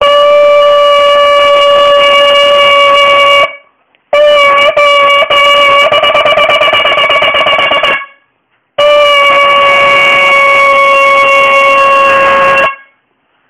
Complimentary Rosh Hashana Shofar Blast Ringtone
Rosh Hashana Shofar BlastÂ (.mp3)
shofar_blast.mp3